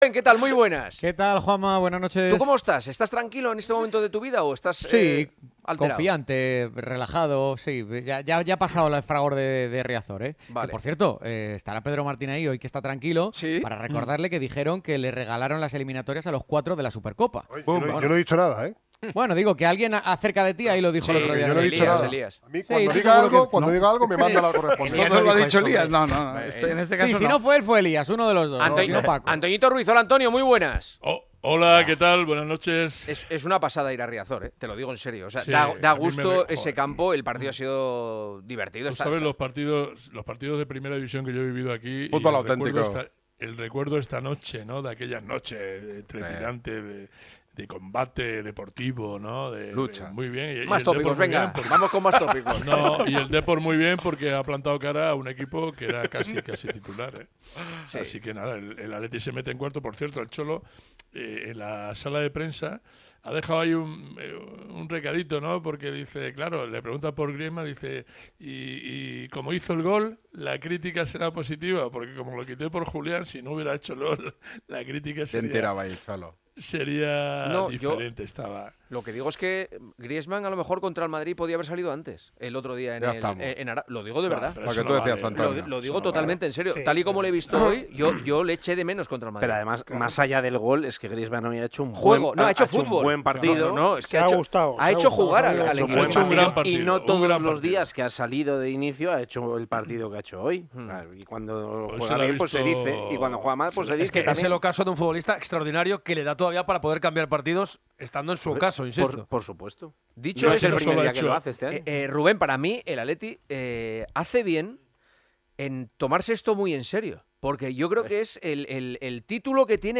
El tertuliano de El Partidazo de COPE analiza la actualidad rojiblanca, marcada por las millonarias ventas de Raspadori y Gallagher y la necesidad de acierto en los fichajes
Juanma Castaño analiza con los tertulianos al Atlético de Madrid, situación en liga y mercado de fichajes